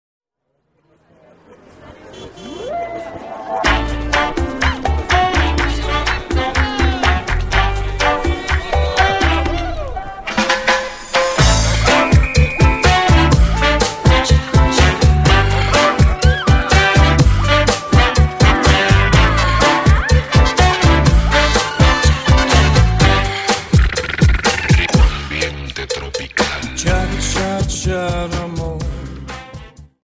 Dance: Cha Cha 31 Song